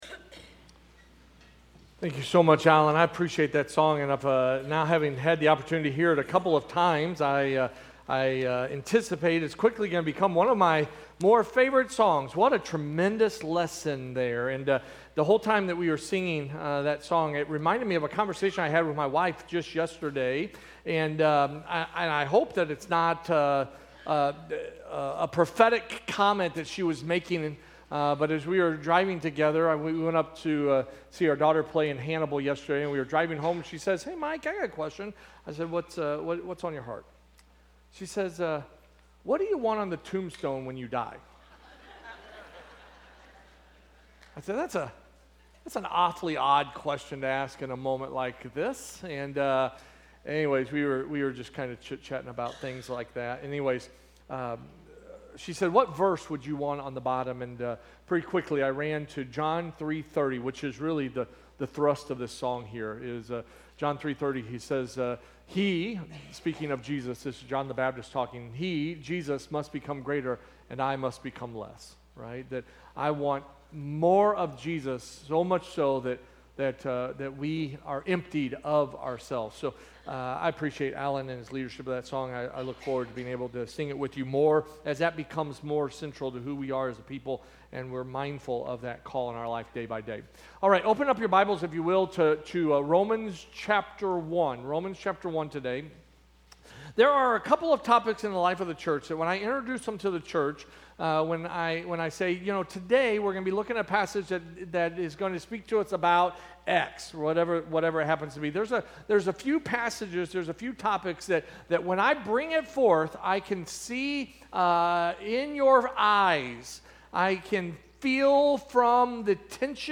Sermons - First Baptist Church O'Fallon